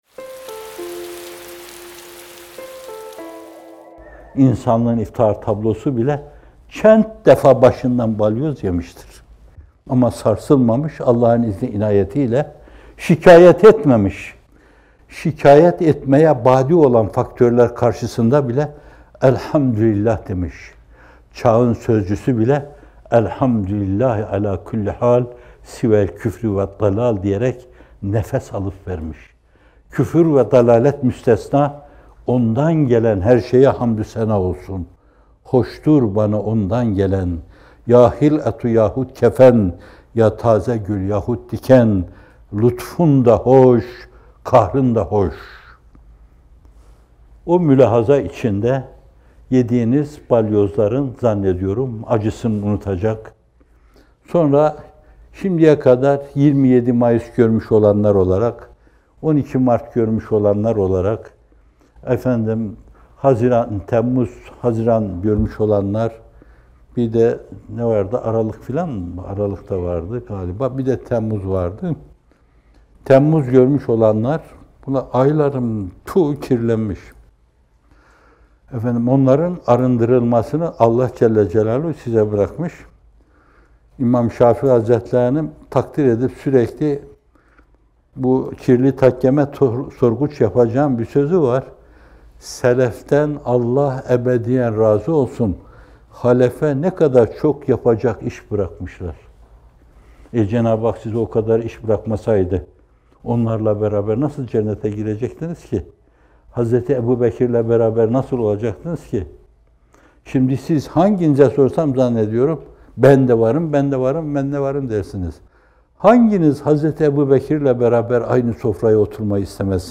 Not: Bu video, 22 Nisan 2019 tarihinde yayımlanan “Şahsiyet Yetimliğinden Kurtuluş” isimli Bamteli sohbetinden hazırlanmıştır.